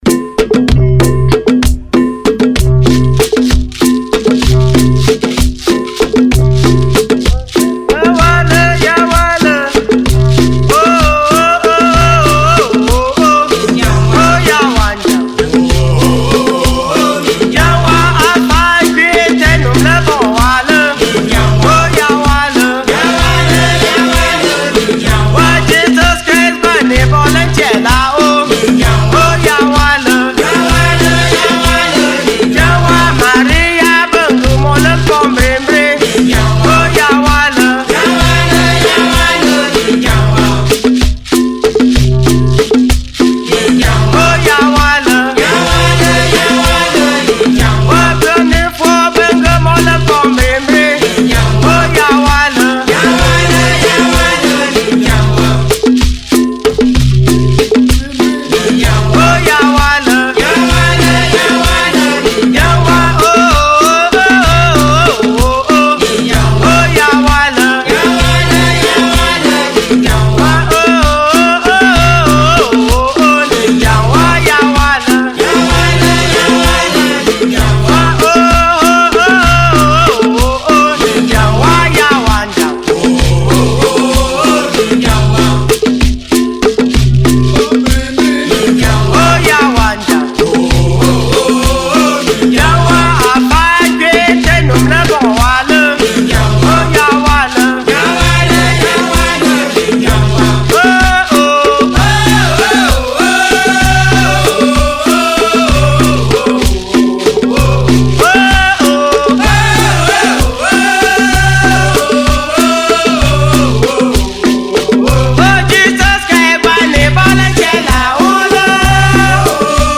Traditional Songs | Bambili Language
Traditional song